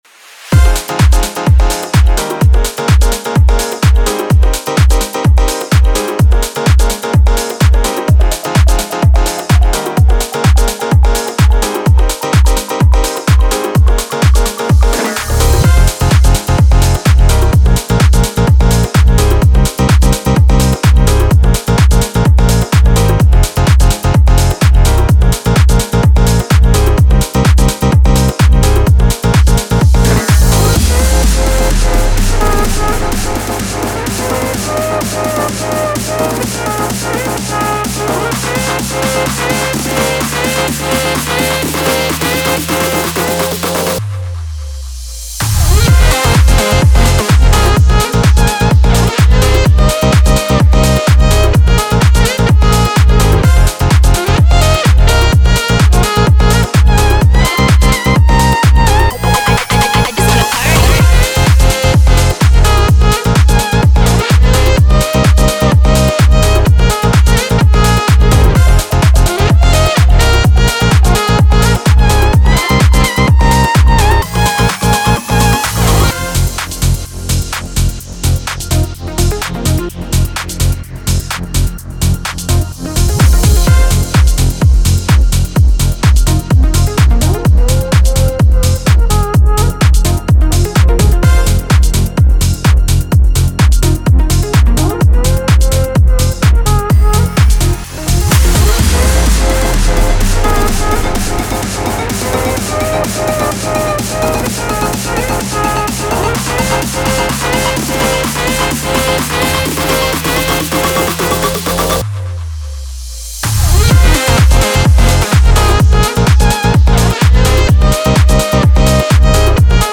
• House
• Future House
• Commercial House